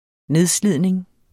Udtale [ ˈneðˌsliðˀneŋ ]